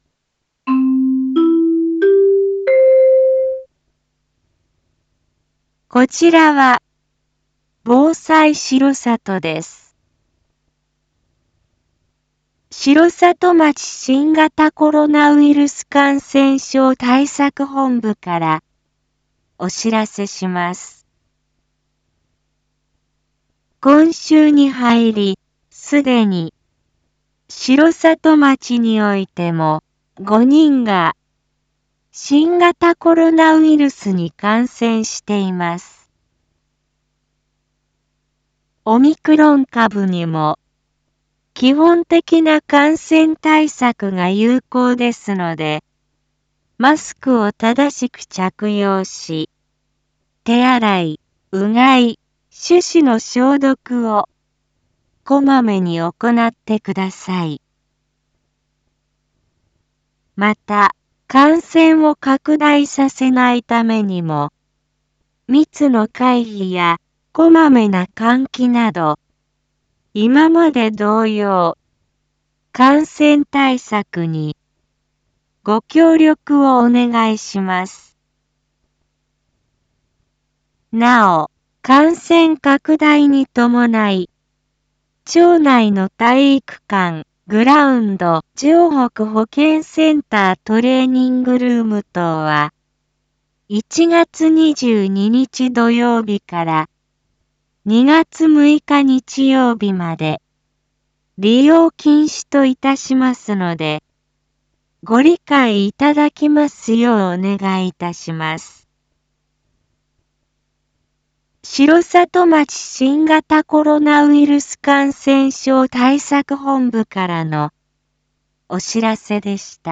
一般放送情報
Back Home 一般放送情報 音声放送 再生 一般放送情報 登録日時：2022-01-20 19:02:15 タイトル：R4.1.20 19時 放送分 インフォメーション：こちらは防災しろさとです。 城里町新型コロナウイルス感染症対策本部からお知らせします。